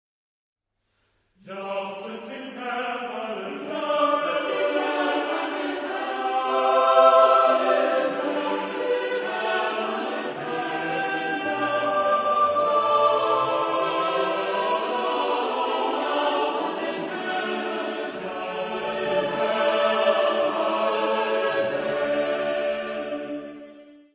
Genre-Style-Form: Psalm ; Romantic ; Sacred
Mood of the piece: andante mosso ; allegro vivace ; majestic
Tonality: G major ; E flat major